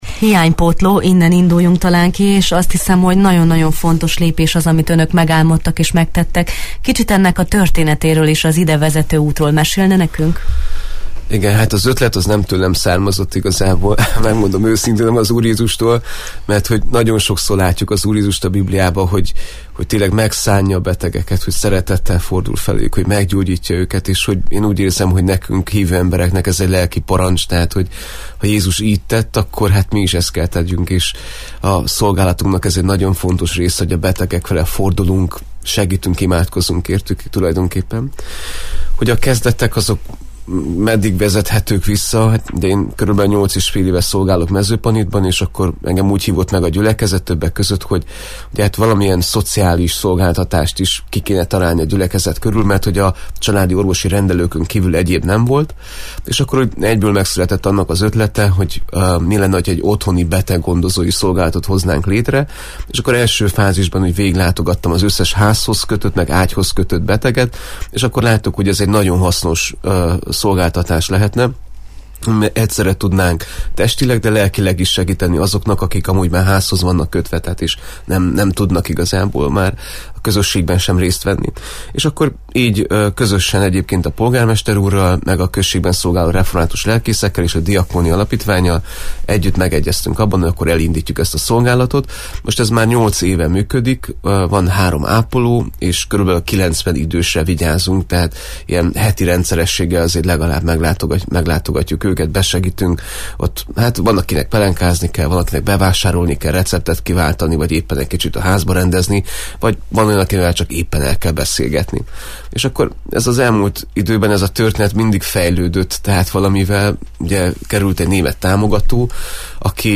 a Jó reggelt Erdély! vendége